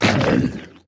Minecraft Version Minecraft Version 1.21.5 Latest Release | Latest Snapshot 1.21.5 / assets / minecraft / sounds / entity / squid / death3.ogg Compare With Compare With Latest Release | Latest Snapshot